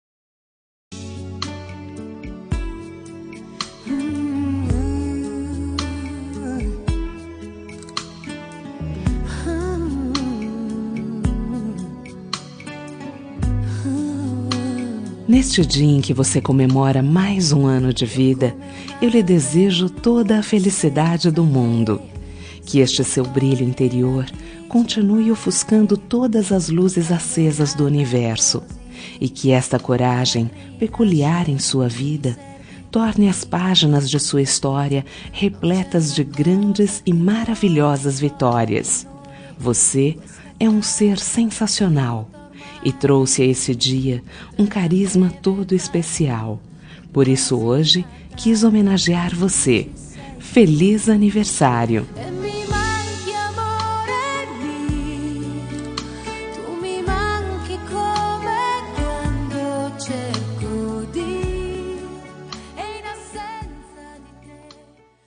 Telemensagem de Aniversário de Pessoa Especial – Voz Feminina – Cód: 1890